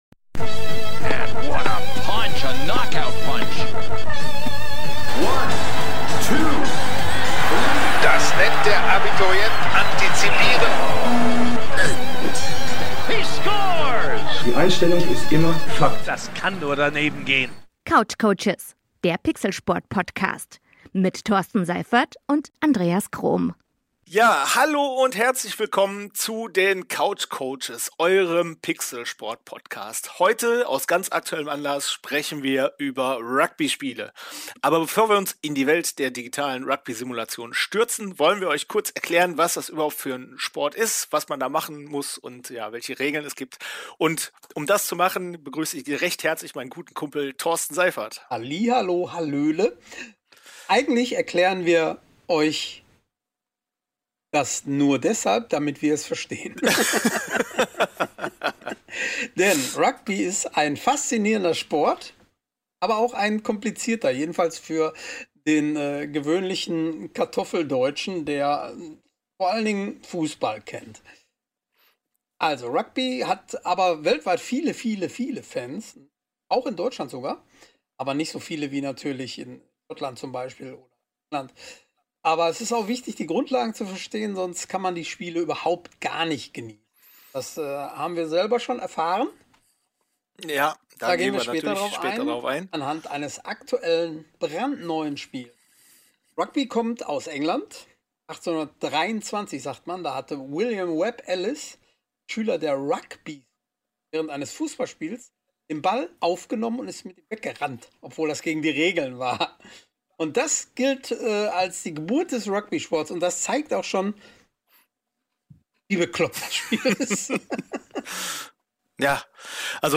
Doch unsere CoachCoaches bekommen fachlich solide Hilfe von einem Studiogast.